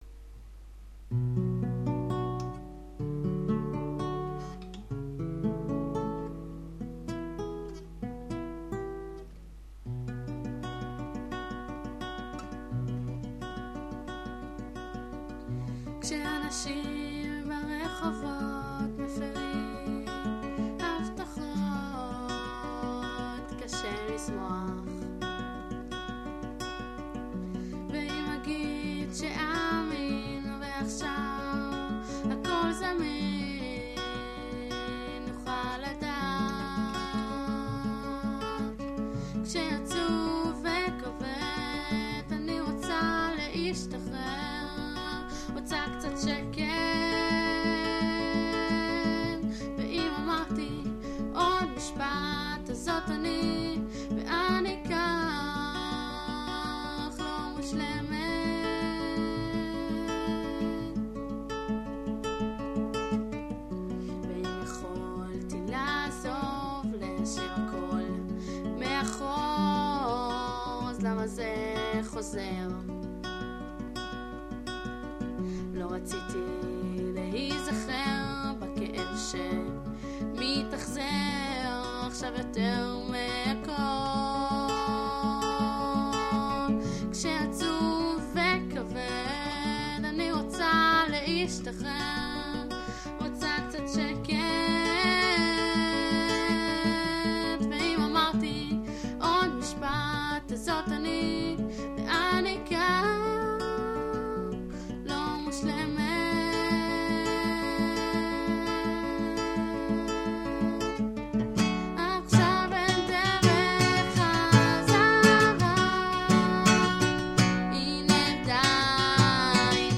אומנם לא שומעים ממש טוב ...
את הקול שלך פחות.... הוא נשמע סגור כזה, לא יודעת......